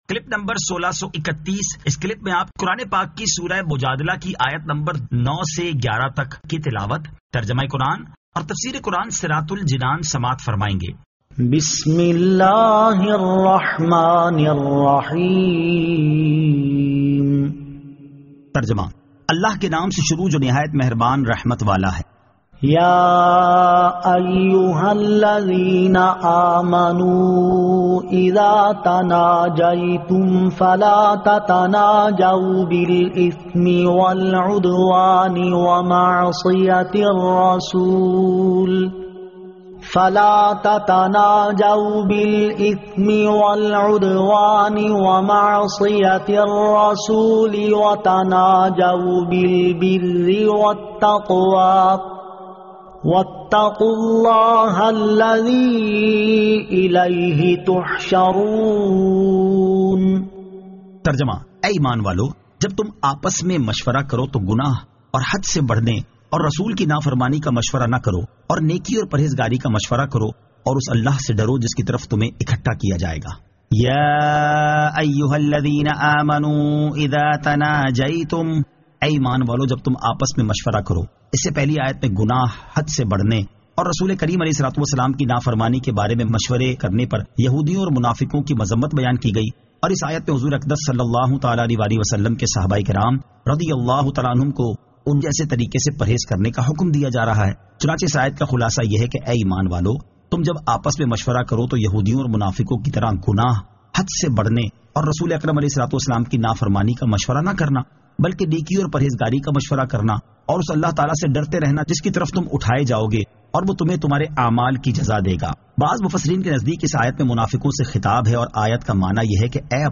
Surah Al-Mujadila 09 To 11 Tilawat , Tarjama , Tafseer